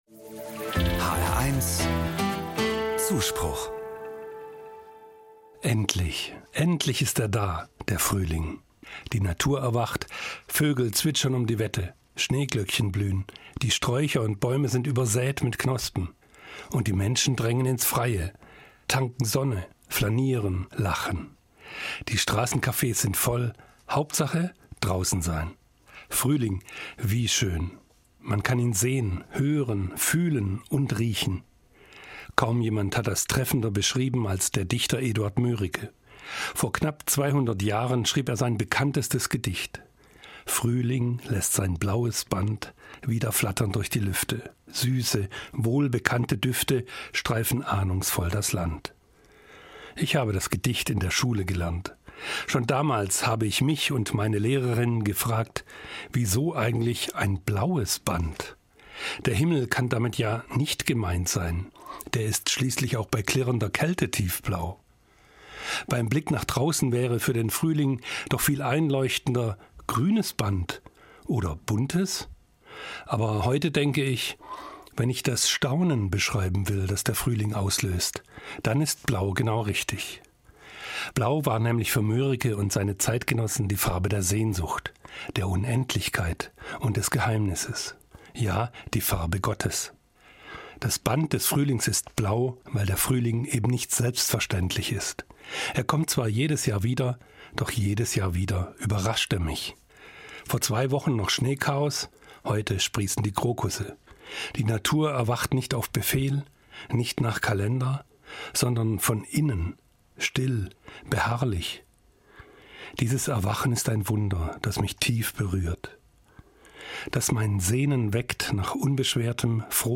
Evangelischer Pfarrer, Frankfurt